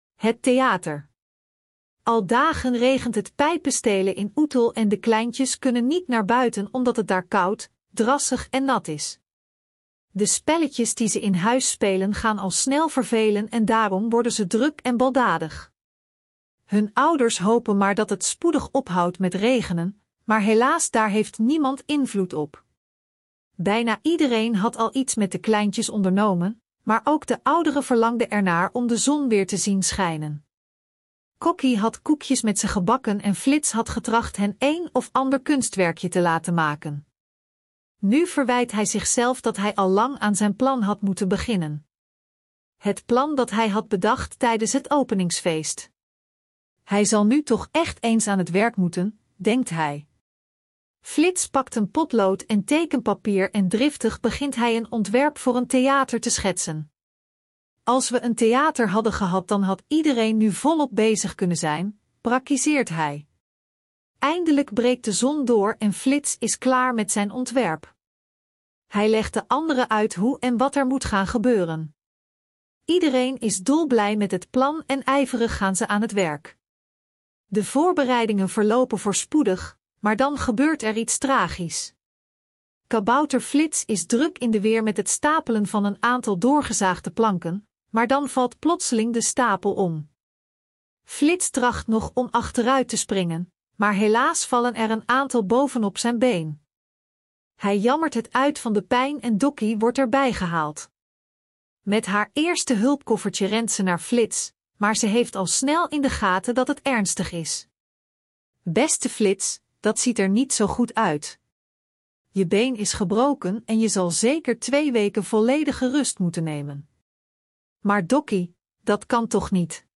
Ook te als luisterverhaal te beluisteren